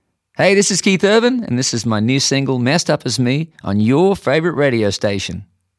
LINER Keith Urban (Messed Up As Me) 1